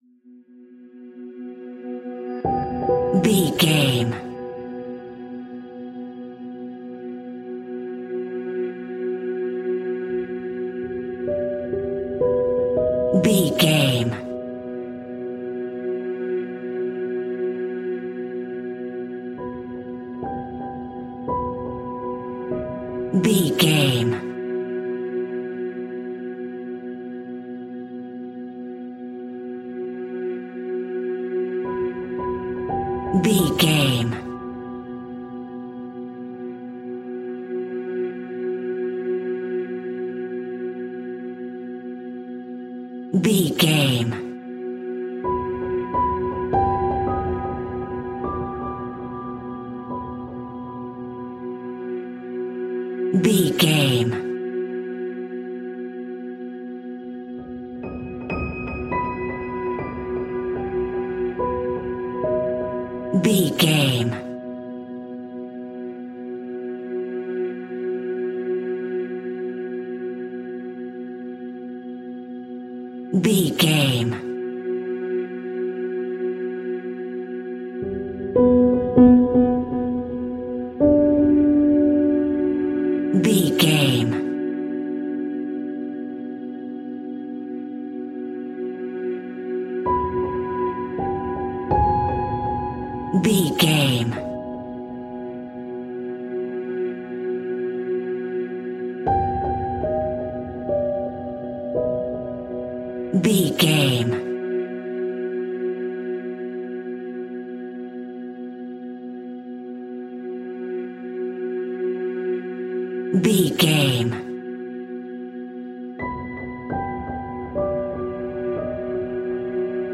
Suspenseful Horror Drone.
Aeolian/Minor
ominous
haunting
eerie
synthesizer
horror music